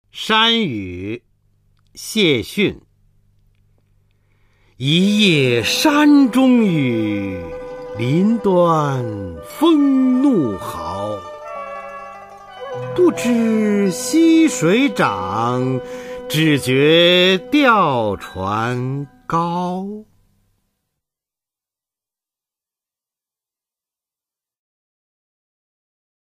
[明代诗词诵读]契逊-山雨 朗诵